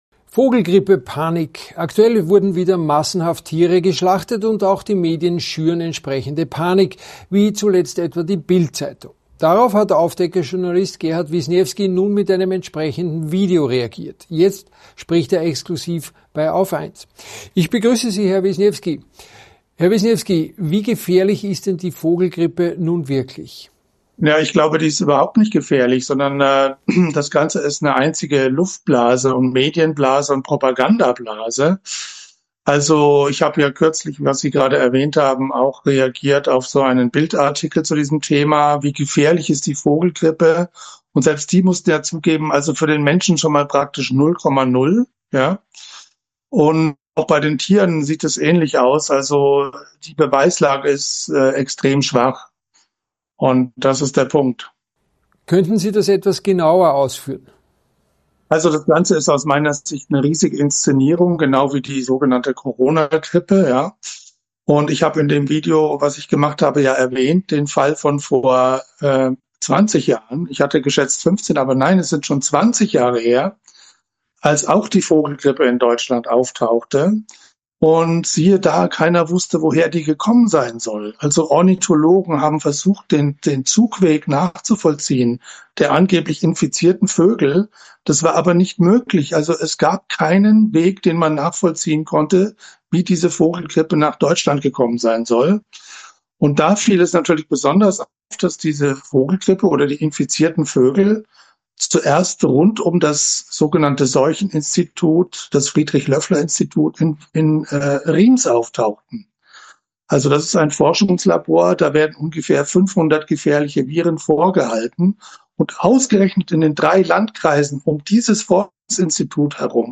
exklusiven Interview